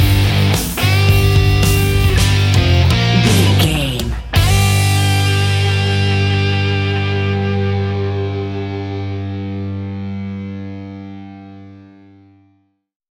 Epic / Action
Aeolian/Minor
hard rock
heavy rock
blues rock
Rock Bass
heavy drums
distorted guitars
hammond organ